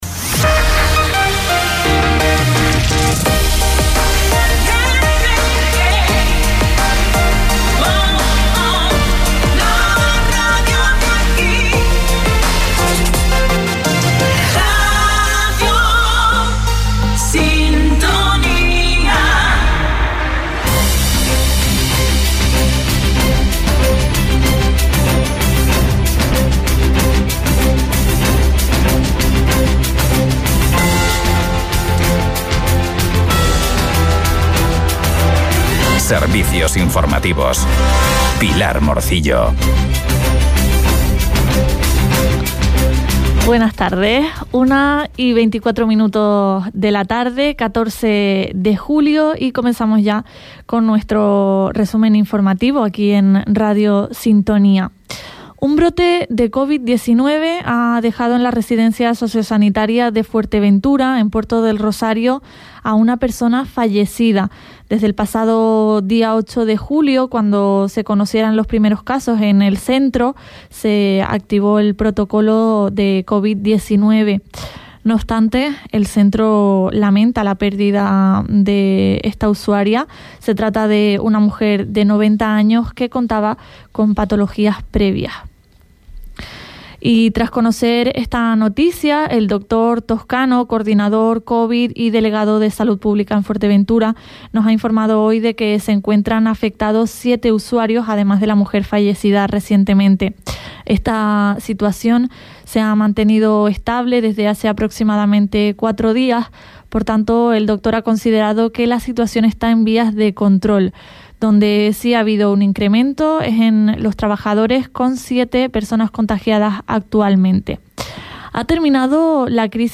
Informativos en Radio Sintonía - 14.07.23 - Radio Sintonía